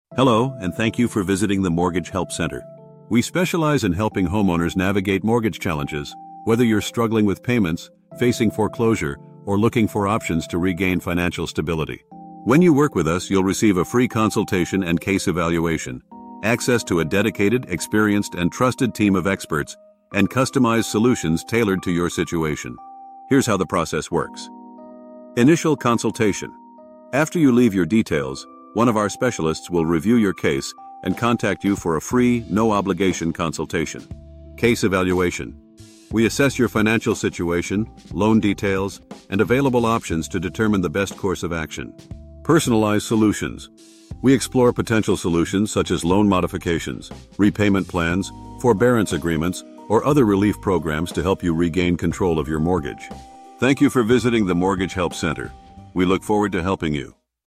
thank_you_for_calling_the_Mortgage_Help_Center-1.mp3